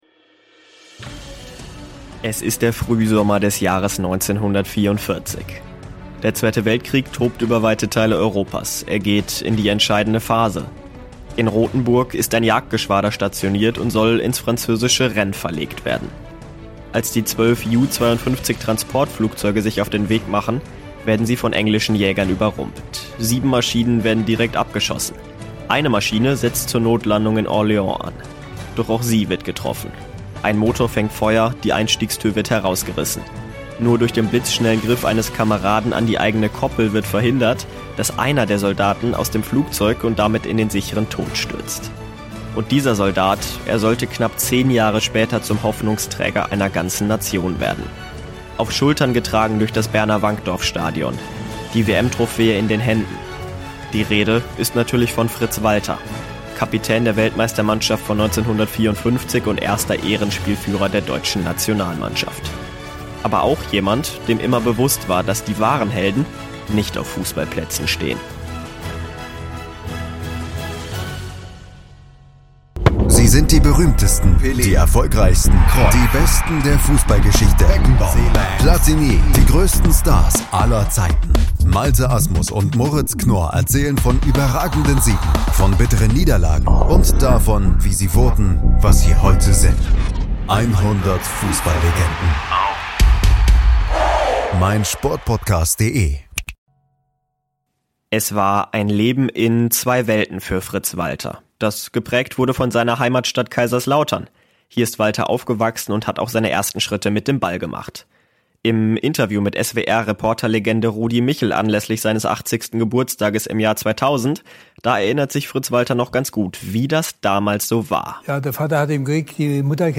Das als Hintergrundmusik verwendete Soundfile trägt ...